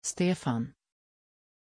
Aussprache von Stefan
pronunciation-stefan-sv.mp3